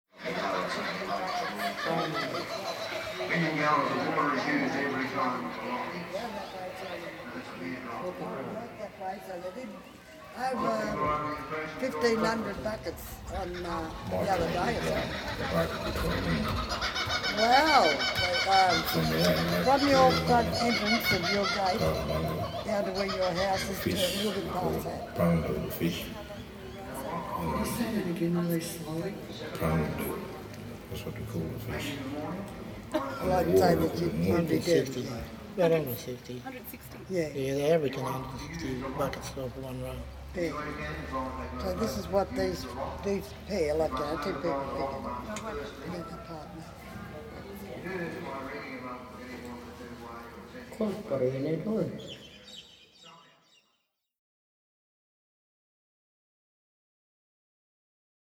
In the sound design a certain random element between the six playback channels will work itself out over the long duration of the installation so that the voices will have varying and unpredictable meetings with each other. This is a fluid polyphony capable of reforming the social politic, the various overlays of stories slipping in and out of silence like the river itself.
stereo mix of 6 -hannel sound installation - Mildura Art Gallery